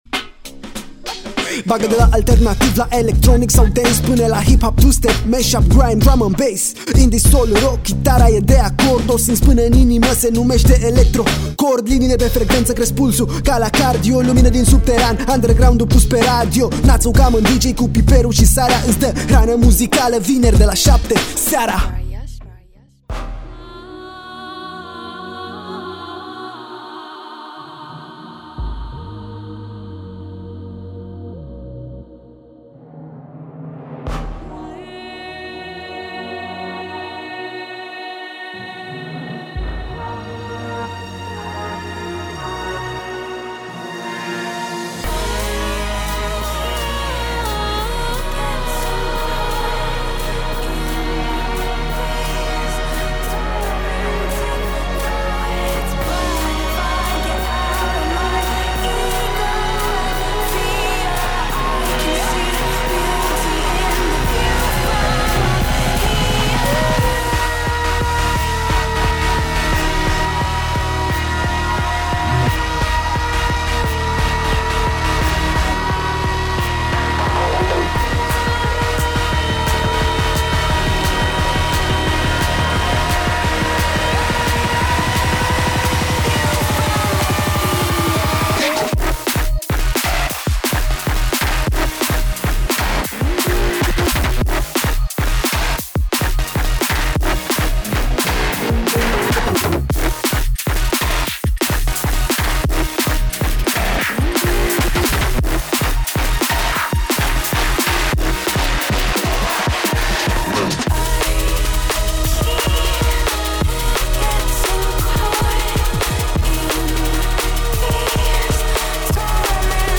muzicii electronice de dans (EDM)
un mix de piese numai potrivite pentru începutul de weekend